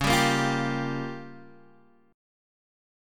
Db6b5 chord